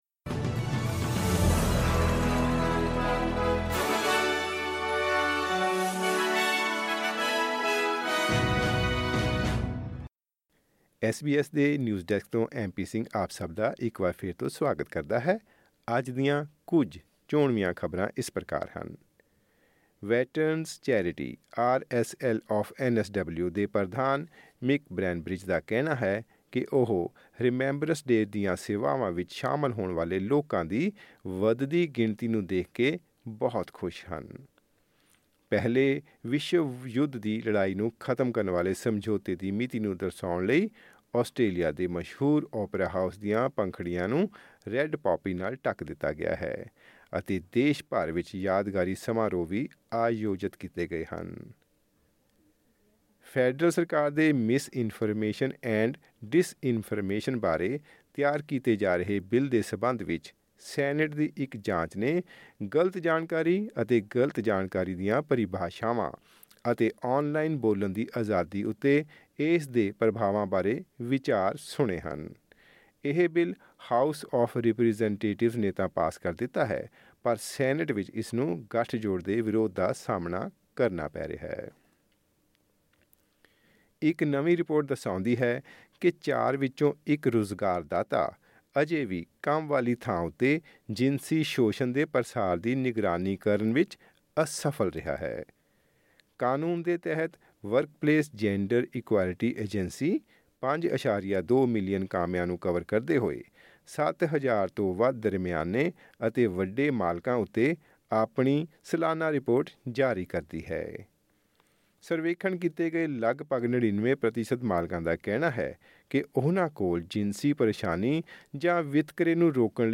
ਐਸ ਬੀ ਐਸ ਪੰਜਾਬੀ ਤੋਂ ਆਸਟ੍ਰੇਲੀਆ ਦੀਆਂ ਮੁੱਖ ਖ਼ਬਰਾਂ: 11 ਨਵੰਬਰ 2024